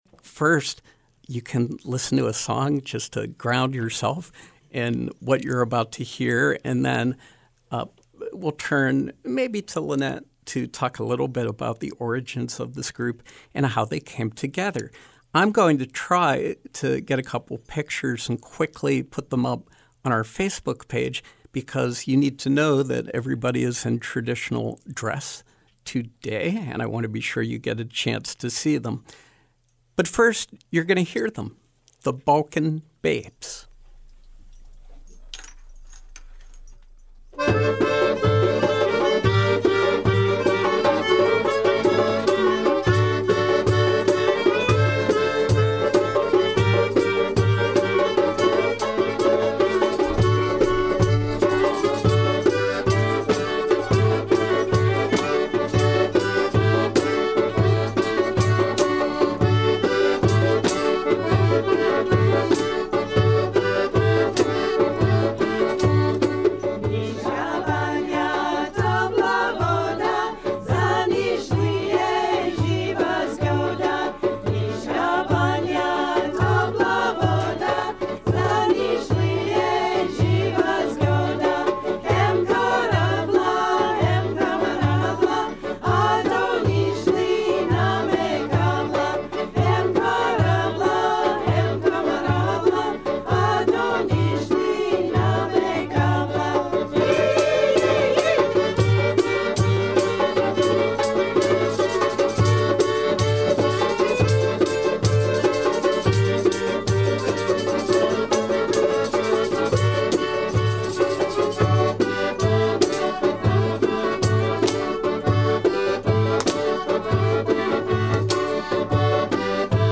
Music and conversation